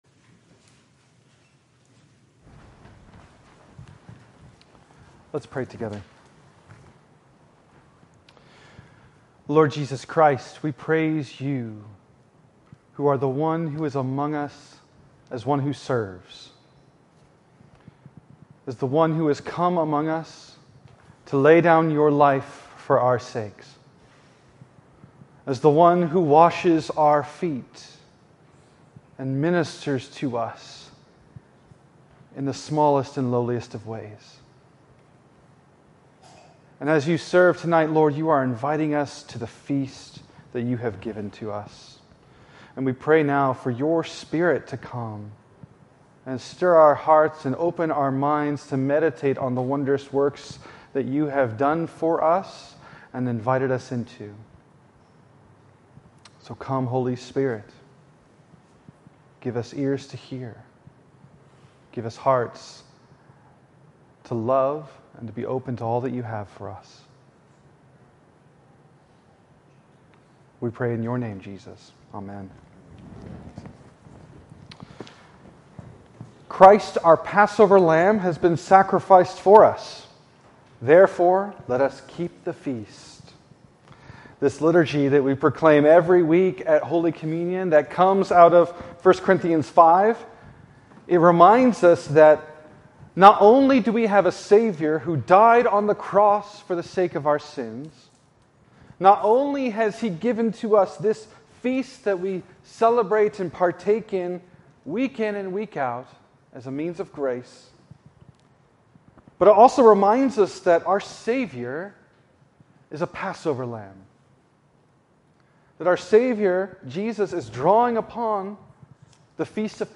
Maundy Thursday